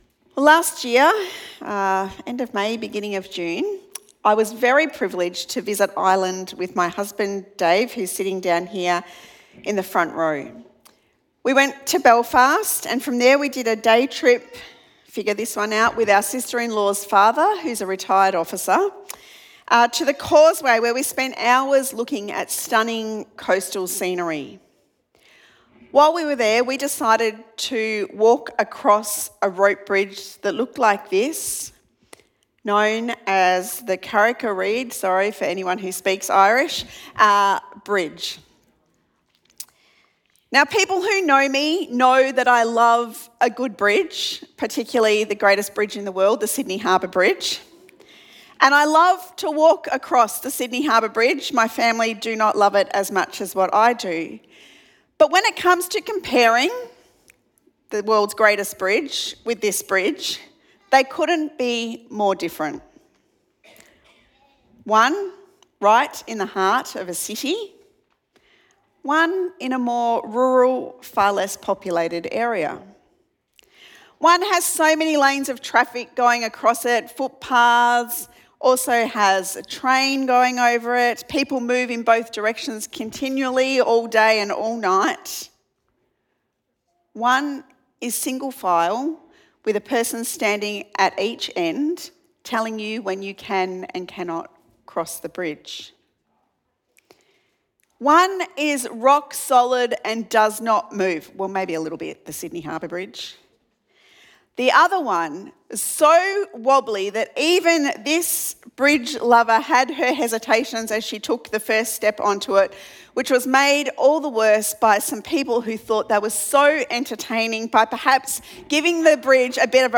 Sermon Podcasts Easter 2026